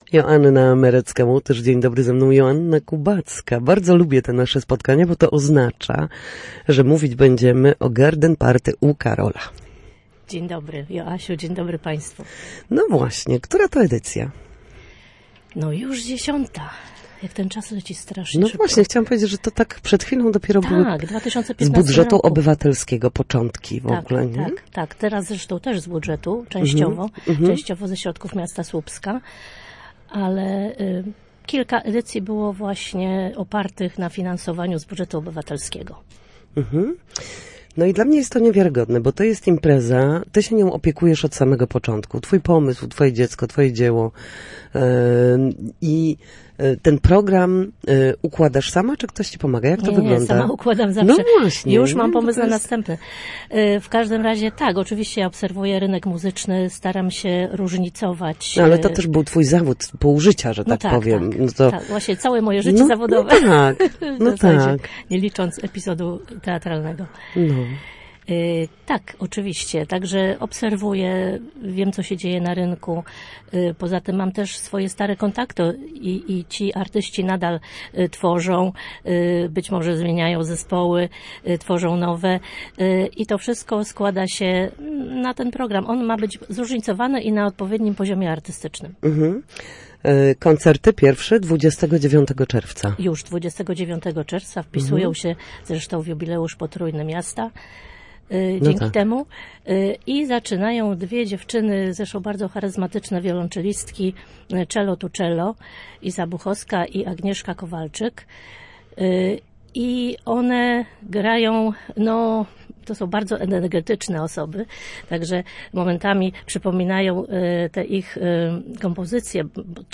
Garden Party u Karola w Parku Waldorfa w Słupsku odbędzie się już po raz dziesiąty. Gościem Studia Słupsk była dziś